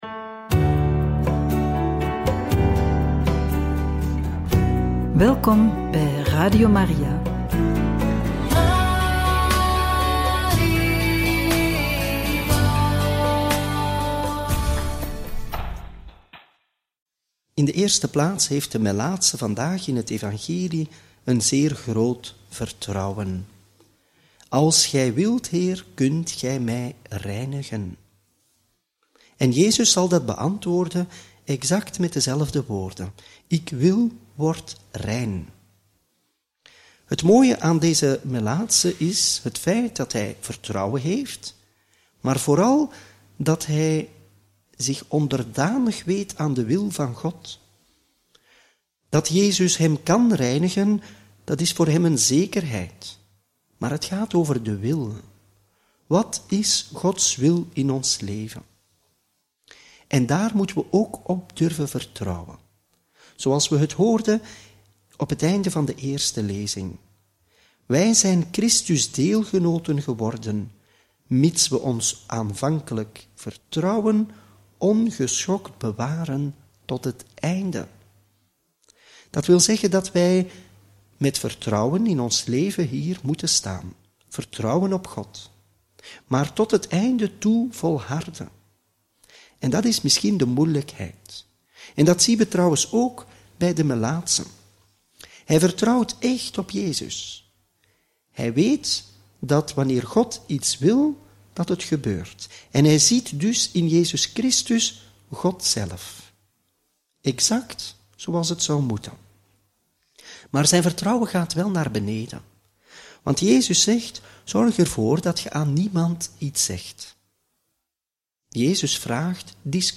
Homilie bij het Evangelie van donderdag 16 januari 2025 – Marcus 1, 40-45